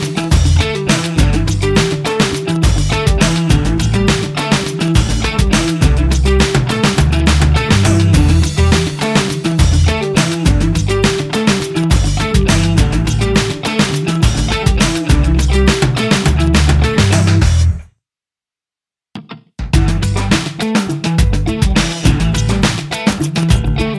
No Backing Vocals or Beatbox Pop (2010s) 3:40 Buy £1.50